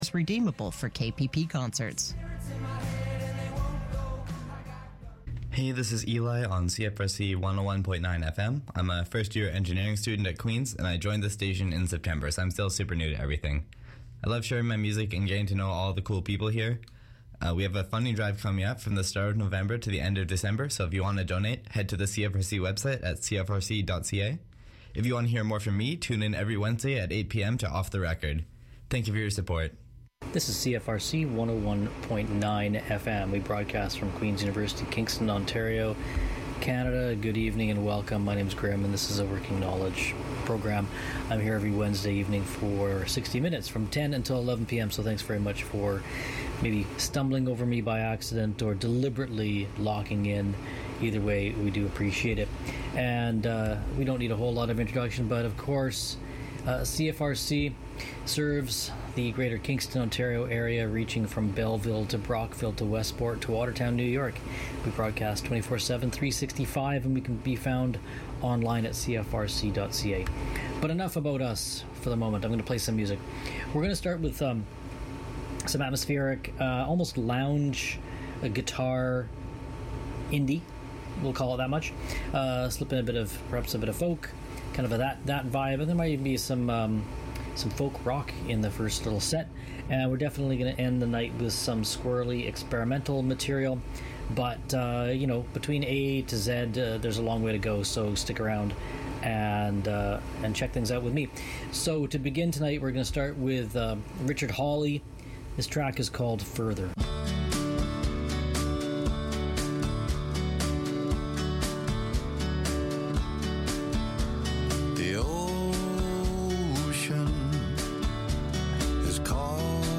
indie, experimental, electronic, post-punk and soul music